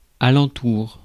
Ääntäminen
Ääntäminen France: IPA: /a.lɑ̃.tuʁ/ Haettu sana löytyi näillä lähdekielillä: ranska Käännöksiä ei löytynyt valitulle kohdekielelle.